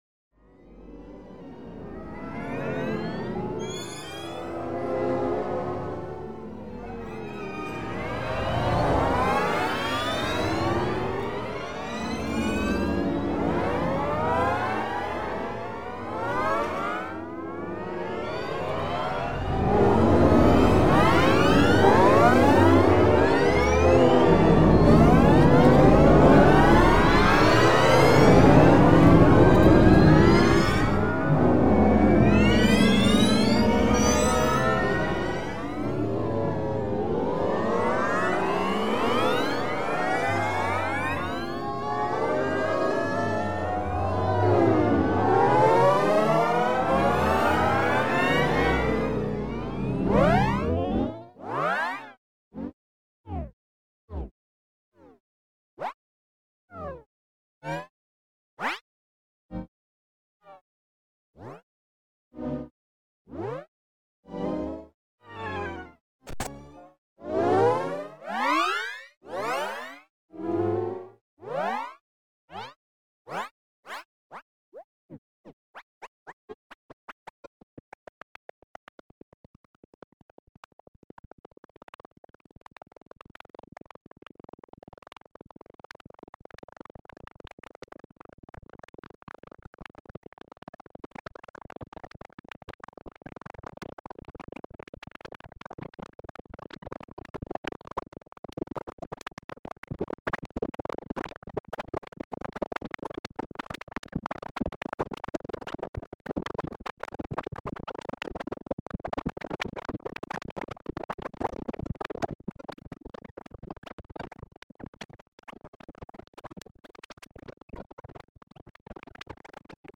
these files are really just the scraps that had accumulated after producing the three compositions for the layer project. i couldn't let them go, gathered them together and am presenting them here as a collection of sound points.